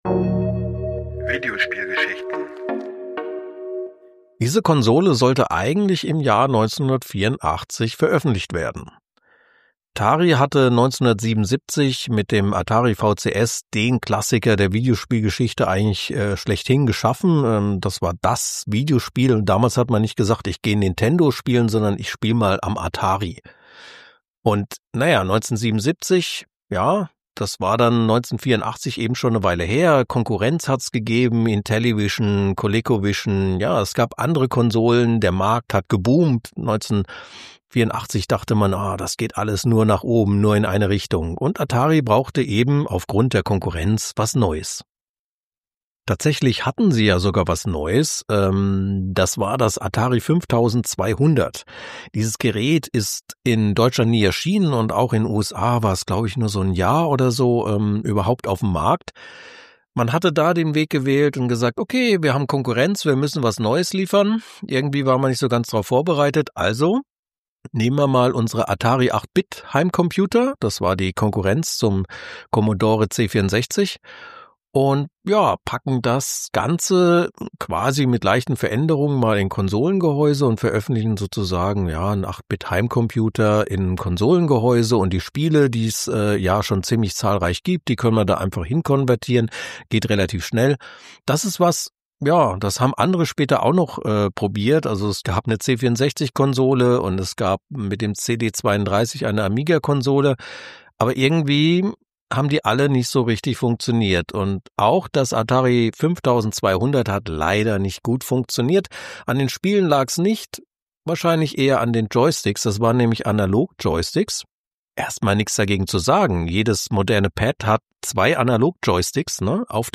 Überarbeitete Originalaufnahme